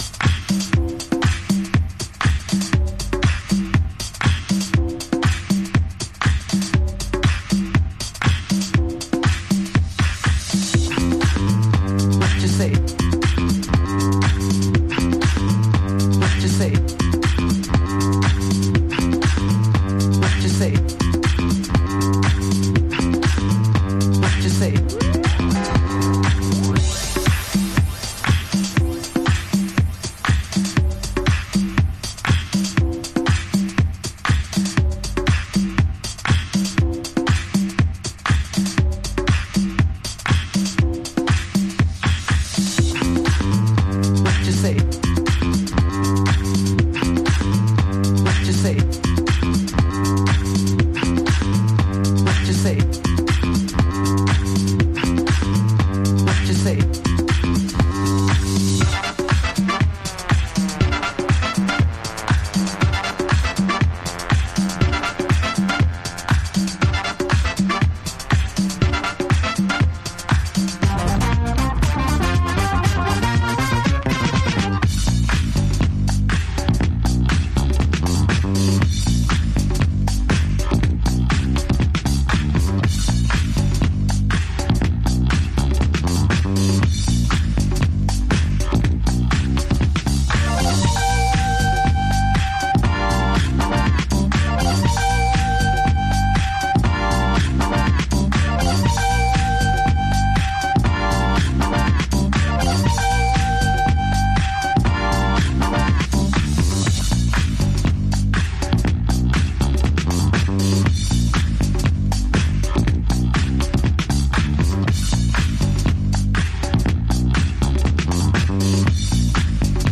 DISCO FUNK GROOVE